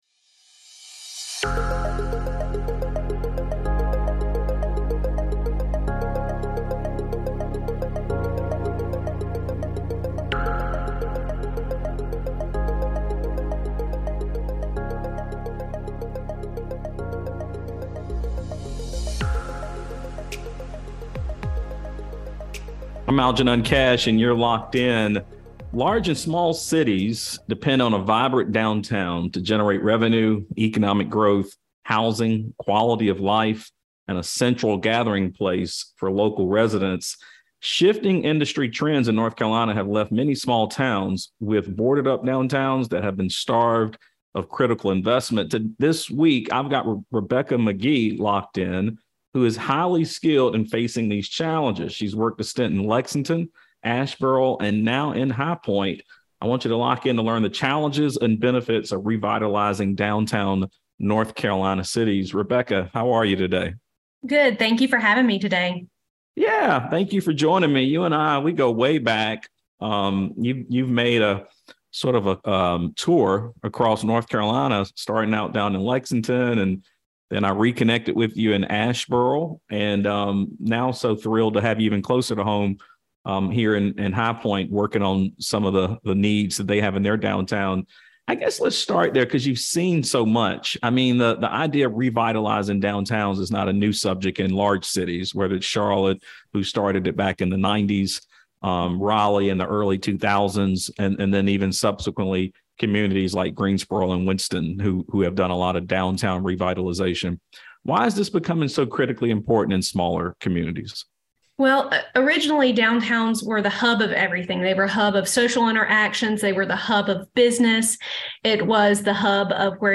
This episode compiles the most insightful moments from our past conversations with five influential voices in North Carolina real estate.